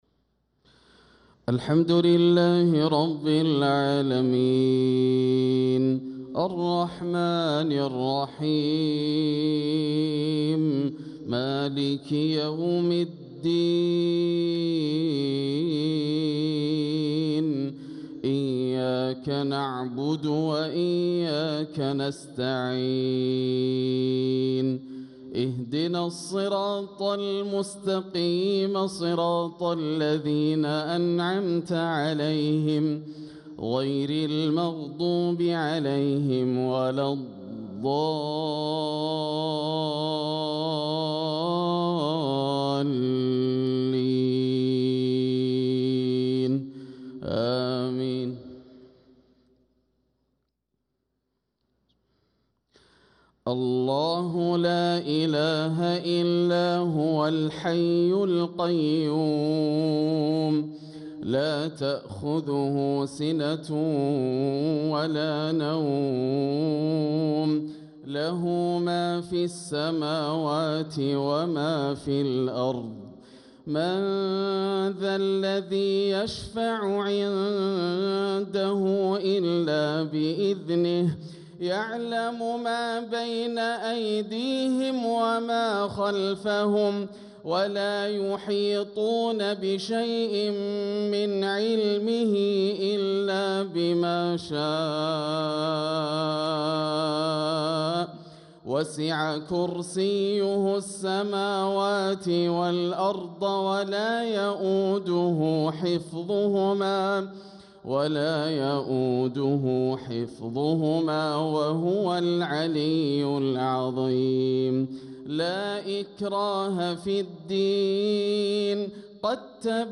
صلاة المغرب للقارئ ياسر الدوسري 17 ربيع الآخر 1446 هـ
تِلَاوَات الْحَرَمَيْن .